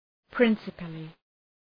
Προφορά
{‘prınsəpəlı}